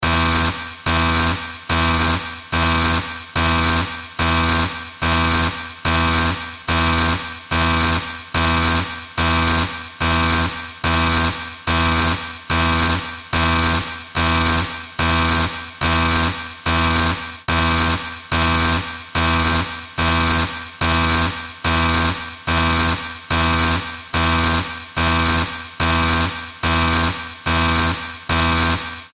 ピッチが速めな危機的な状況の警報アラーム音。